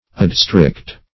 Adstrict \Ad*strict"\, v. t.